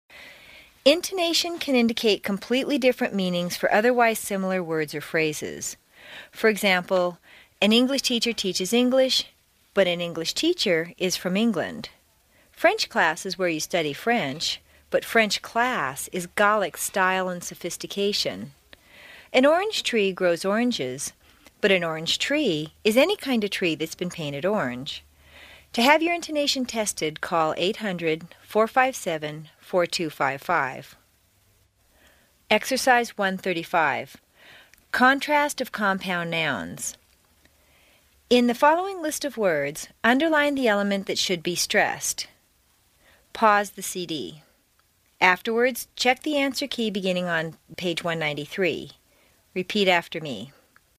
美式英语正音训练第29期:Exercise 1-35 Contrast of Compound Nouns 听力文件下载—在线英语听力室
在线英语听力室美式英语正音训练第29期:Exercise 1-35 Contrast of Compound Nouns的听力文件下载,详细解析美式语音语调，讲解美式发音的阶梯性语调训练方法，全方位了解美式发音的技巧与方法，练就一口纯正的美式发音！